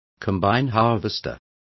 Also find out how cosechadora is pronounced correctly.